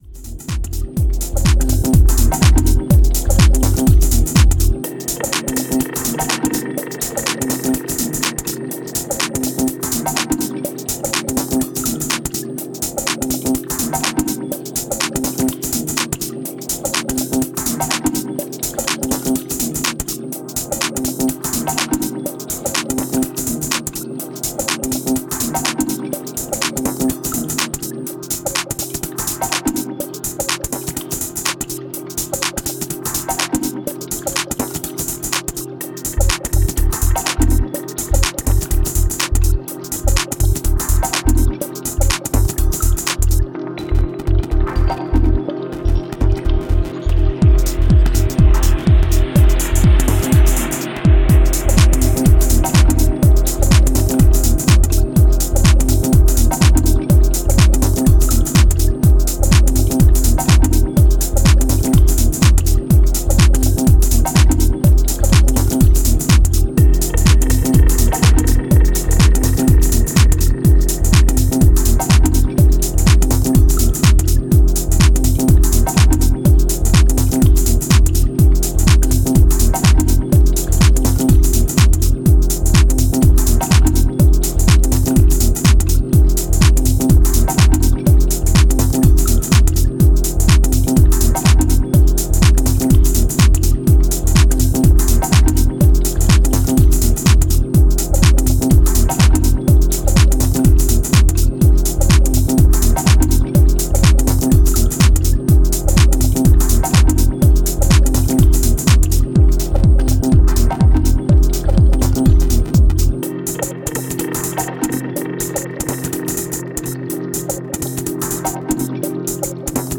Romanian duo